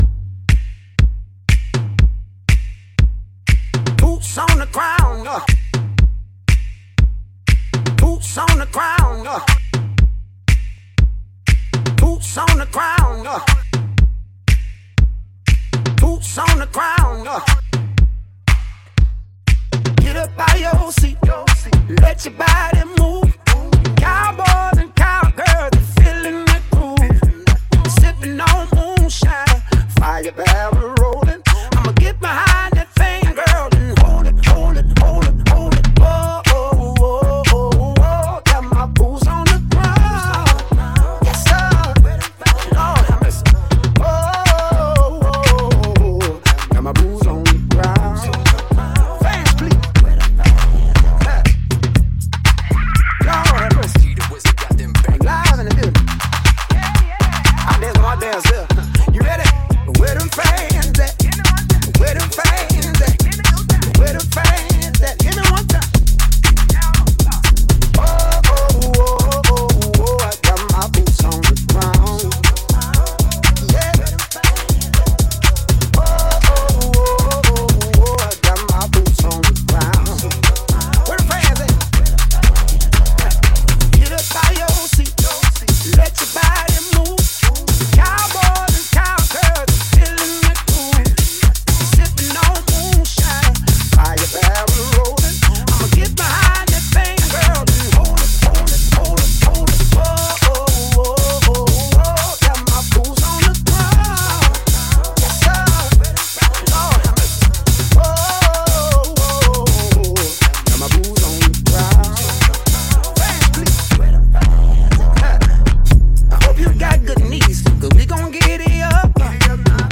Exclusive remix